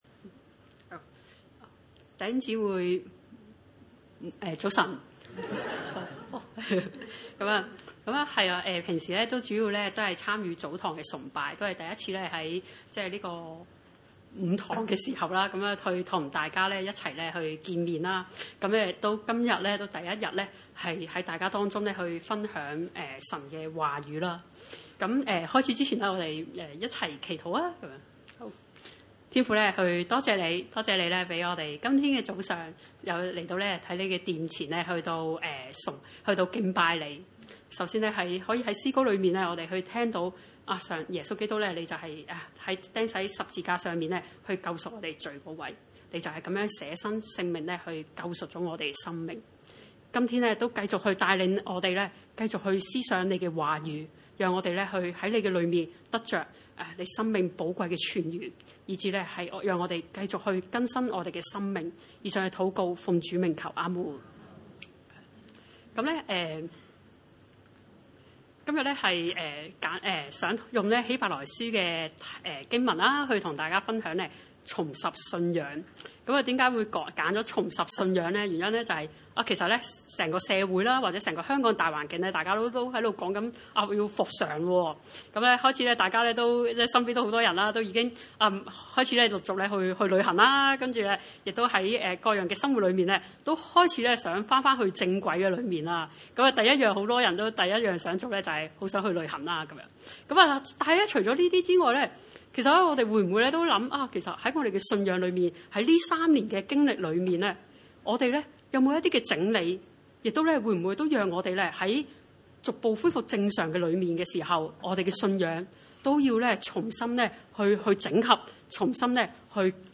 經文: 希伯來書 10:19-25 崇拜類別: 主日午堂崇拜 19 弟兄們，我們既因耶穌的血得以坦然進入至聖所， 20 是藉著他給我們開了一條又新又活的路，從幔子經過，這幔子就是他的身體。